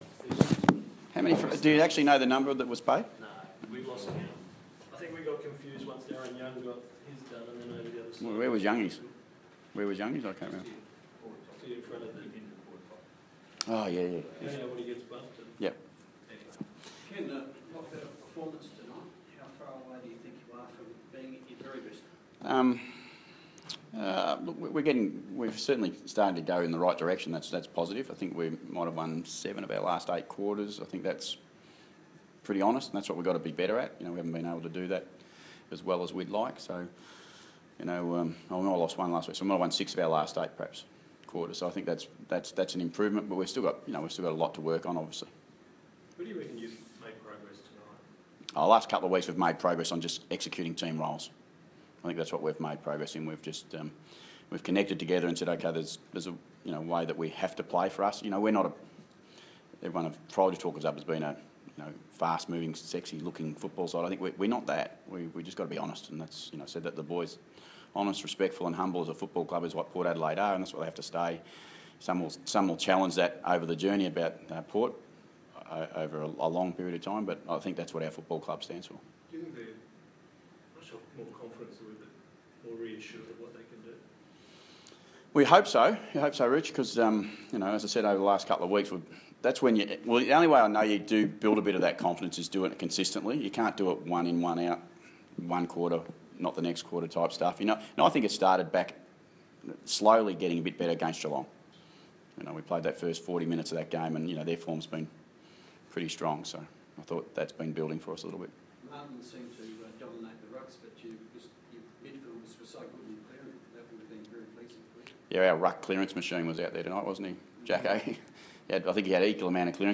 Ken Hinkley Post-match Press Conference - Sunday, 8 May, 2016